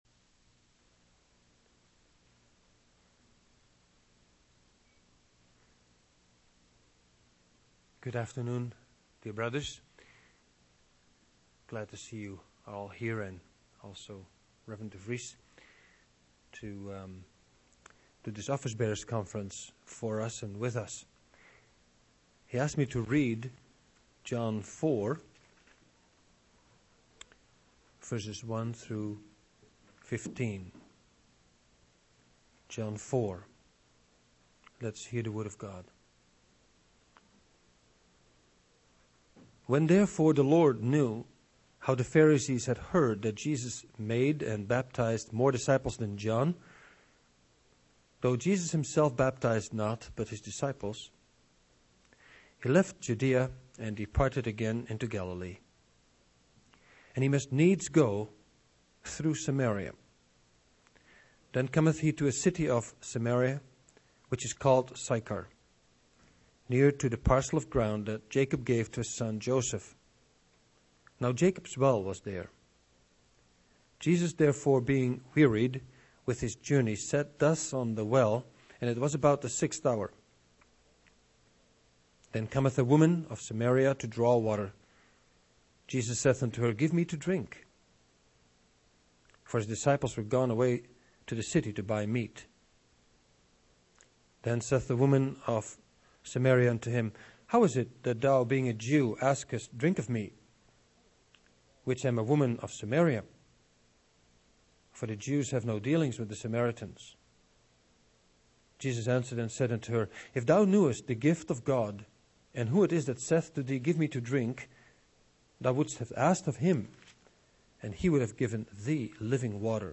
Office Bearer's Conference